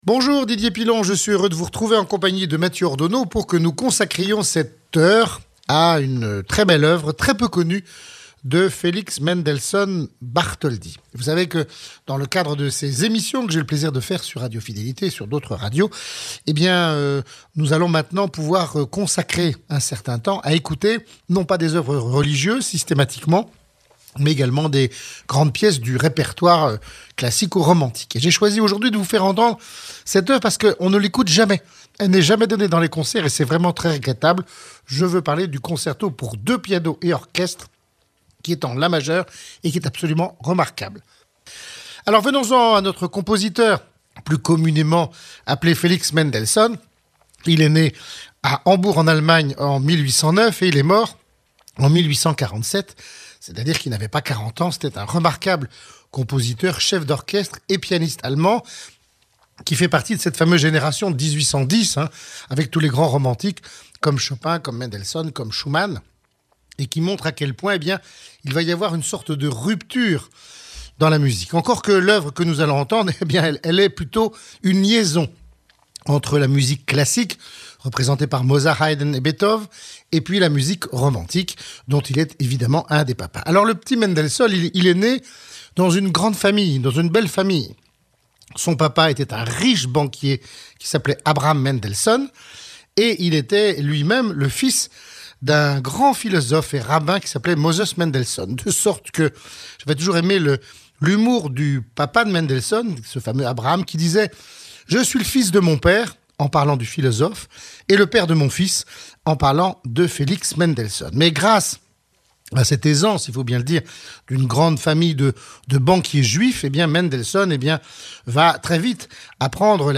DP-Felix Mendelssohn - Concerto pour 2 pianos et orchestre